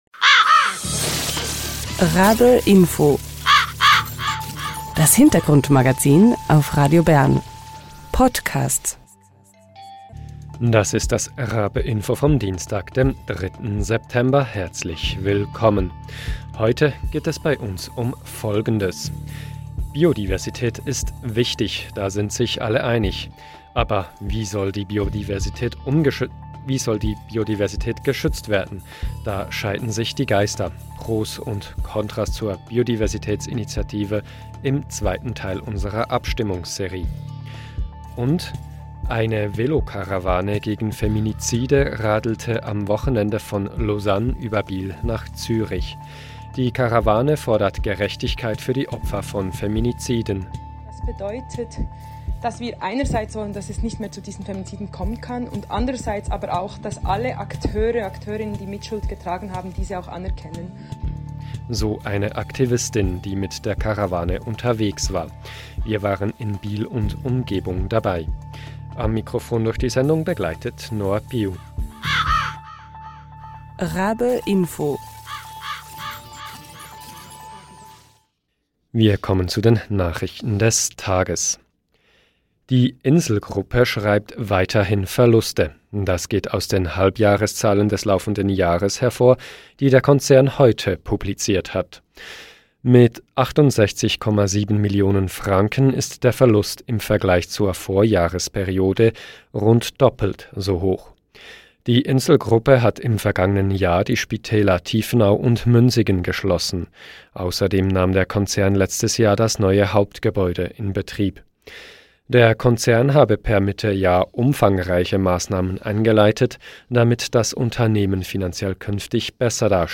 Heute steht die Biodiversitätsinitiative auf dem Programm. Wir hören die Stimmen von Befürworter*innen und Gegner*innen und sprechen ausserdem mit einem Professor für aquatische Ökologie darüber, was die Wissenschaft zum Stand der Biodiversität in der Schweiz sagt. Dann begleiten wir eine Gruppe von Aktivist*innen, die mit einer Velokarawane Tatorte von Feminiziden besucht und Gerechtigkeit für die Opfer fordert.